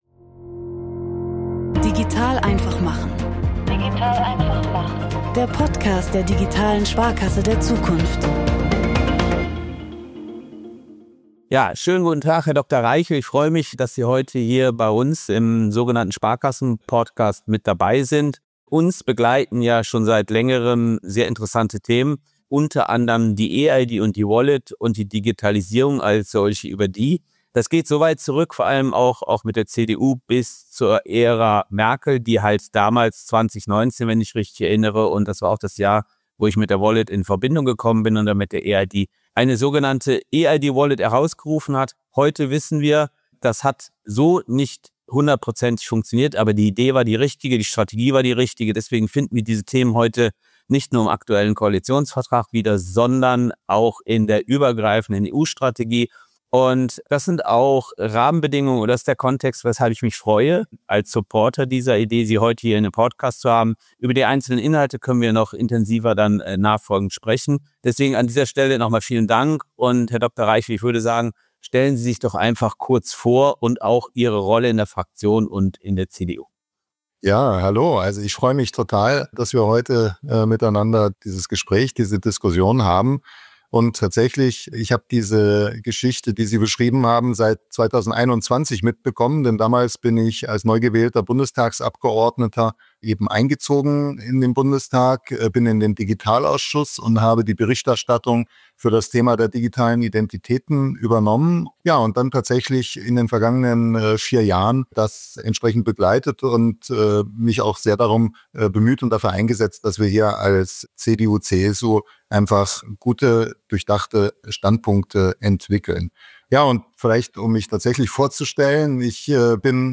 Im Gespräch mit ... 07: Dr. Markus Reichel, CDU, Mitglied des Deutschen Bundestags ~ digital.einfach.machen. Podcast